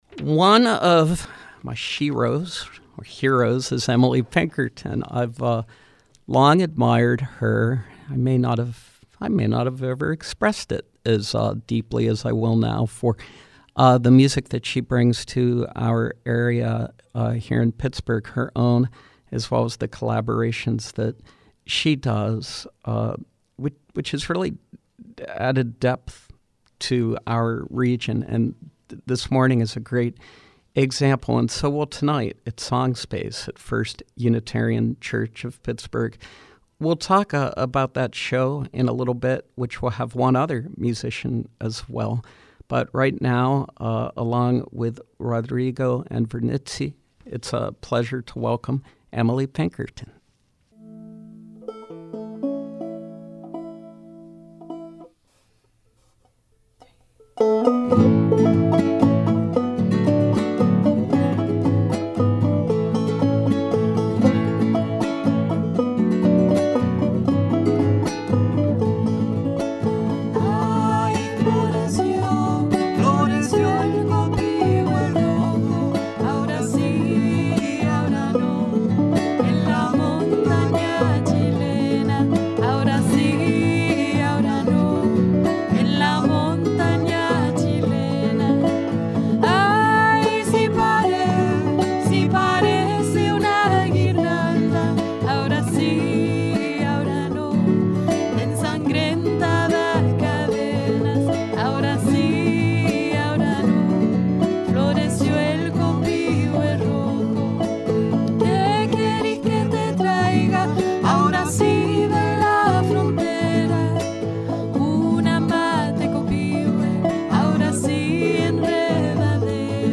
roots music from North and South America